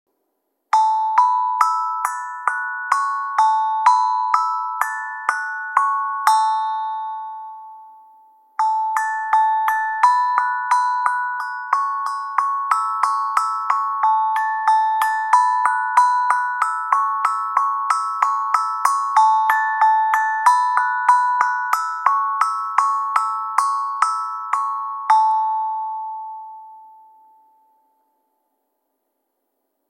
Meinl Sonic Energy 3" Pocket Steel Tongue Drum, A Major, 6 Notes, 440 Hz, Venus Flower, Navy Blue (PSTD2NBVF)